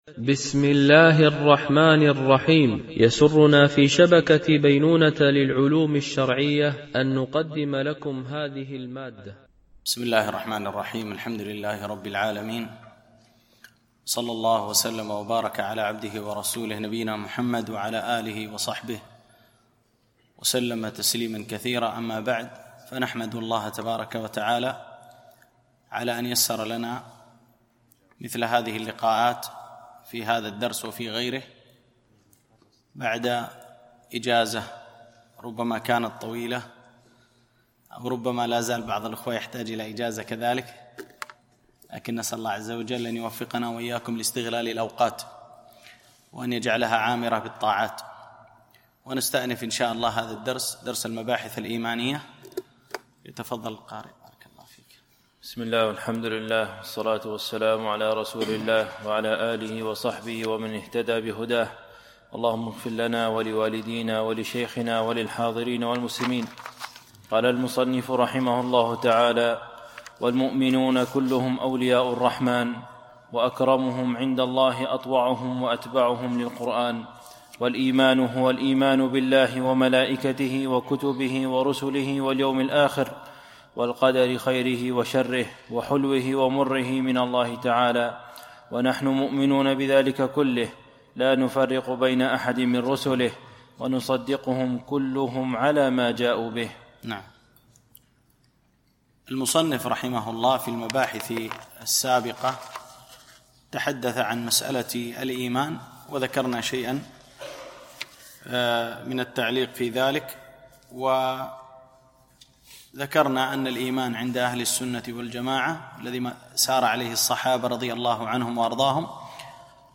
مباحث إيمانية - الدرس 9